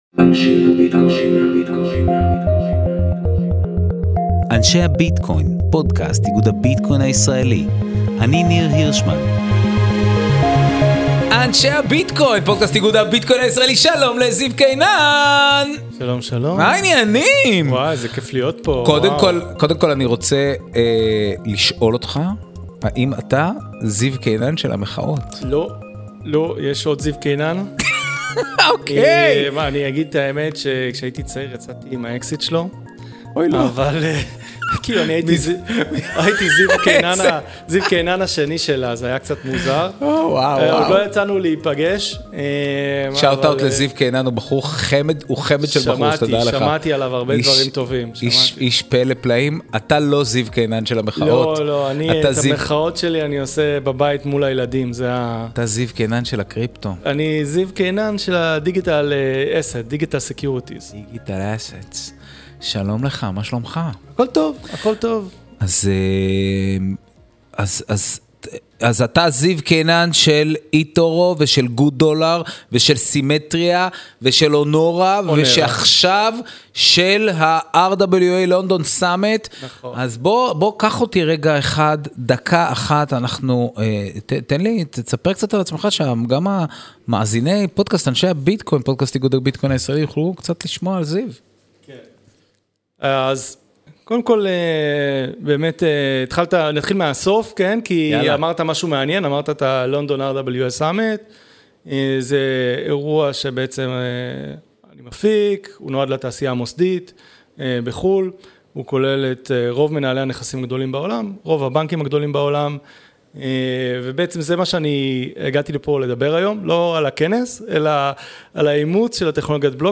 השבוע בפודקאסט אנשי הביטקוין – שיחה